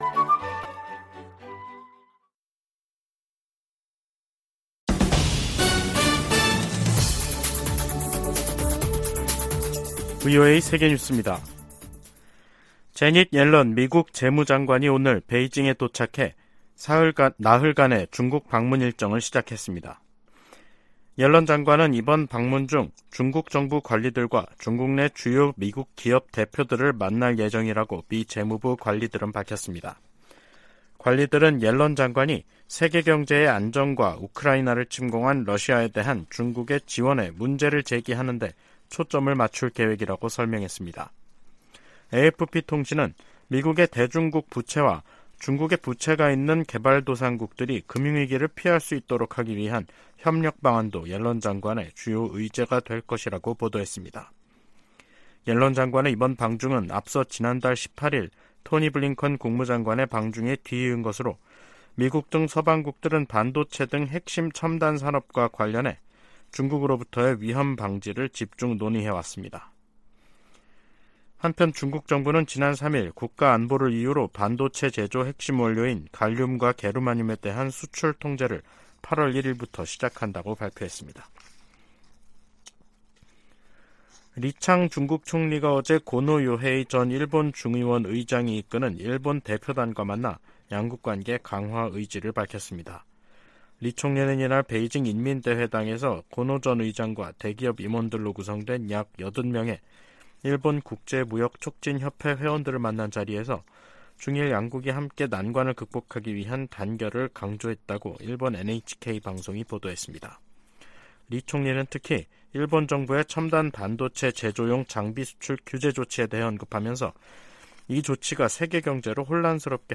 VOA 한국어 간판 뉴스 프로그램 '뉴스 투데이', 2023년 7월 6일 2부 방송입니다. 한국 군 당국이 서해에서 인양한 북한의 정찰위성을 분석한 결과 군사적 효용성이 전혀 없다고 평가했습니다. 한중 외교 당국 대화가 재개된 것은 '위험 관리'에 들어간 최근 미중 간 기류와 무관하지 않다고 미국의 전직 관리들이 진단했습니다. 중국의 반간첩법 개정안은 중국 내 탈북민 구출 활동을 봉쇄할 수 있는 악법이라고 탈북 지원단체들과 브로커들이 말했습니다.